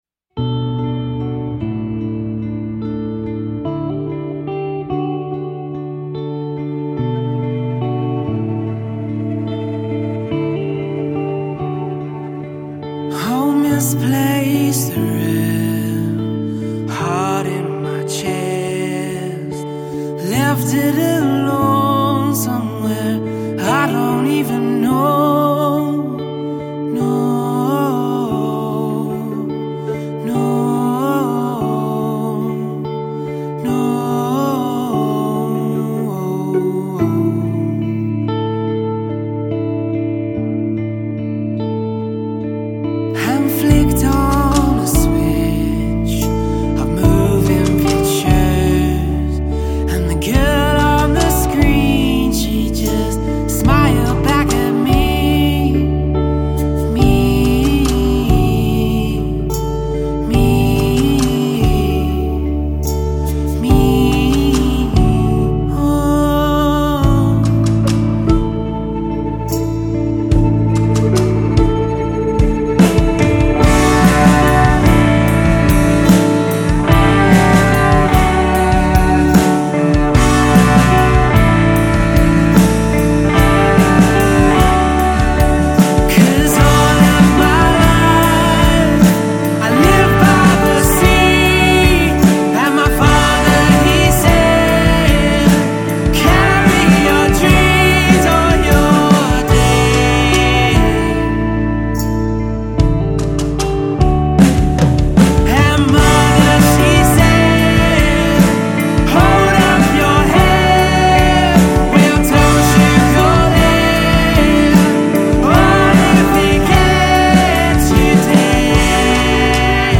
folk-rock trio